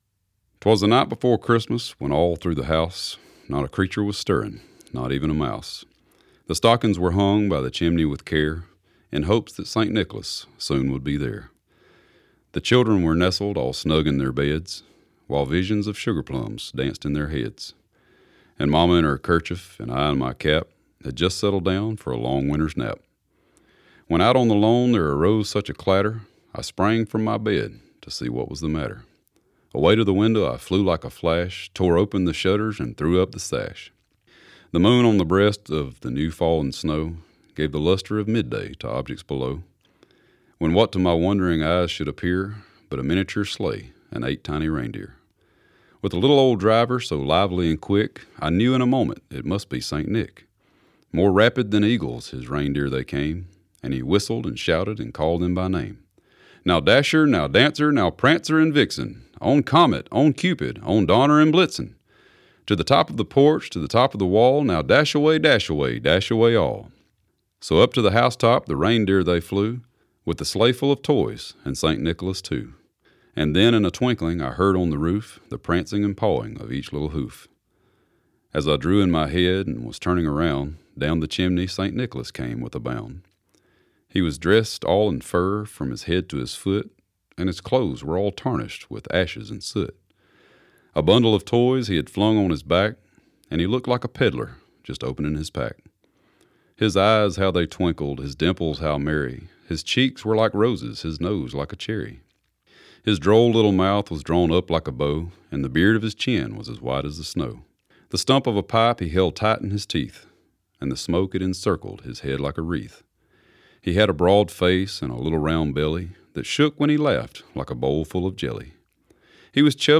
Here’s a solo read of “‘Twas the Night Before Christmas” from Josh Turner.
Josh-Turner-Twas-The-Night-Before-Christmas-read.mp3